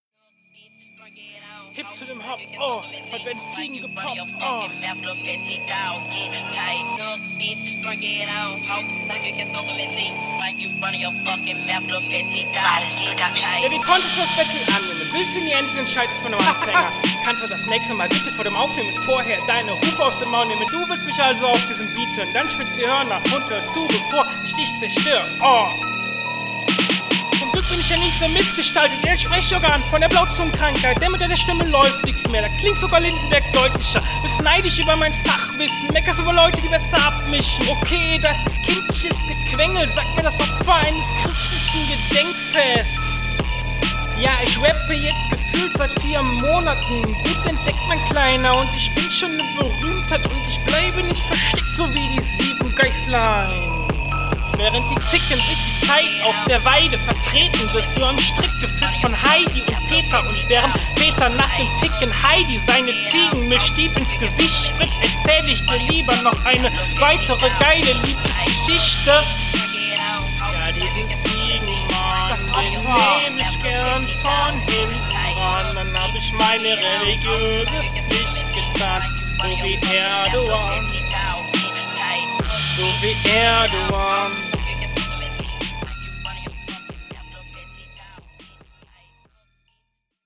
Flow: Noch eine Fehler im Flow vorhanden, dran bleiben, weiter üben, dann wird das.